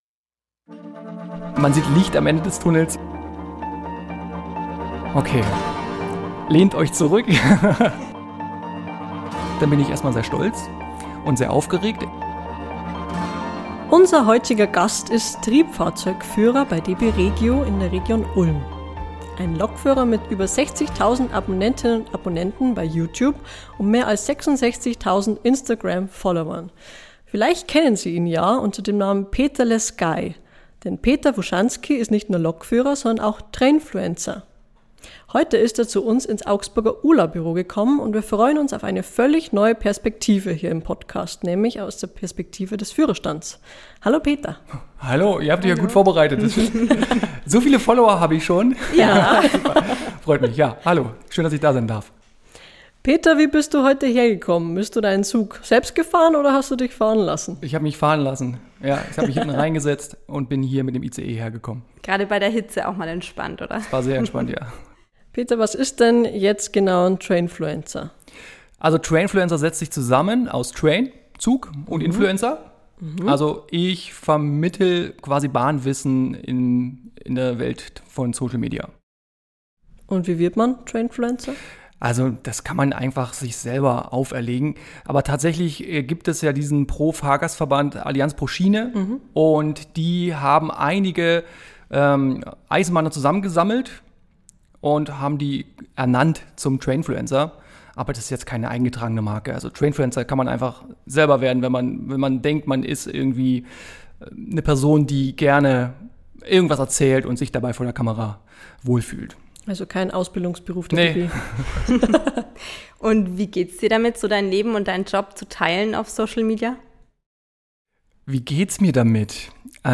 Ein Gespräch über Reichweite, Realität und Respekt.